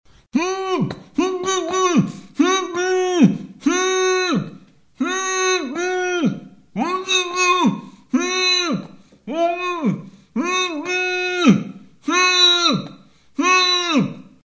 Звуки мужского крика
мужик с заклеенным ртом кричит